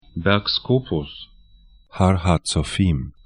Aussprache
Berg Scopus bɛrk 'sko:pʊs Har HaZofìm har ha tsɔ'fi:m he Berg / mountain 31°47'N, 35°15'E